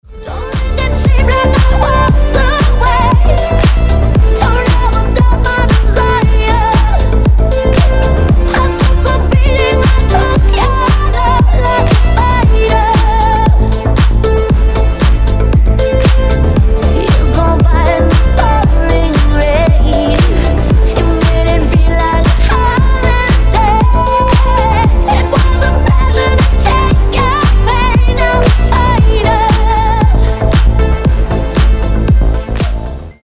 • Качество: 320, Stereo
Electronic
Club House
красивый женский голос